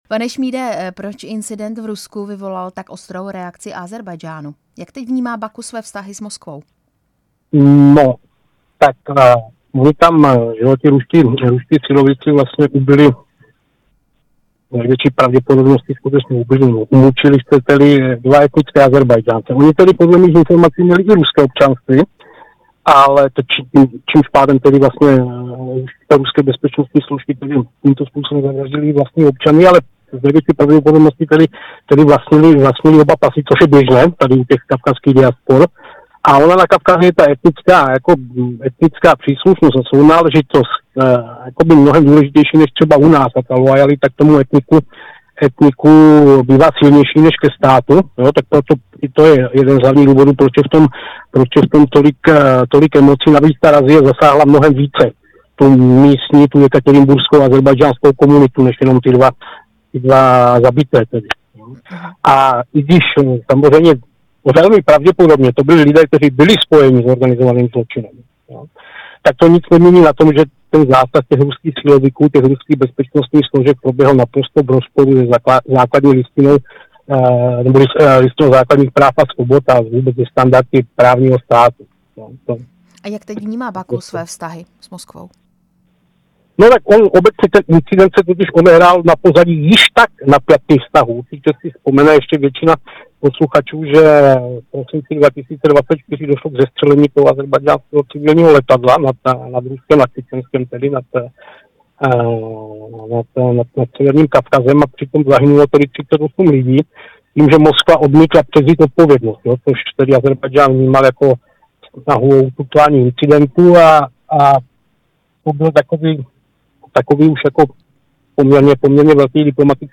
Rozhovor